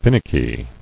[fin·ick·y]